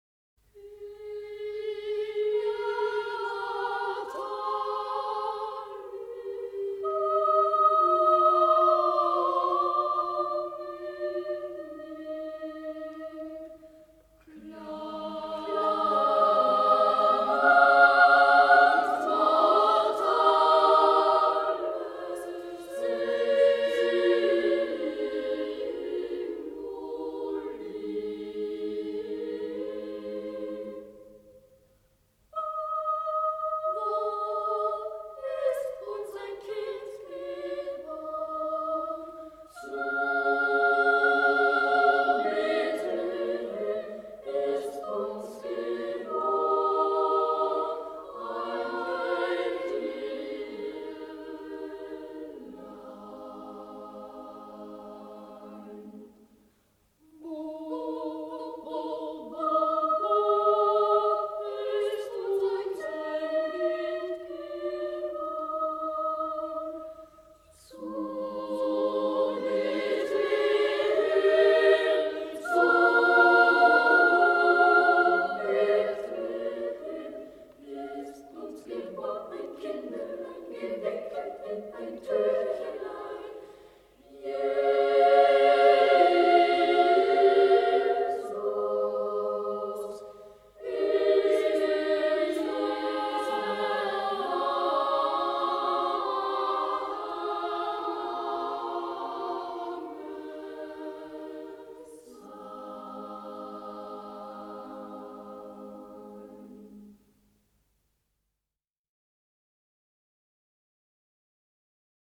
Die Wiener Sängerknaben - 04.12.
In natali Domini ist eine Weihnachtsmotette von Michael Praetorius.
Die Antwort kommt im Wechselgesang, vielleicht von Engeln.
This is a Christmas motet by Michael Praetorius: In natali Domini.
The answer comes in a chorus, perhaps of angels.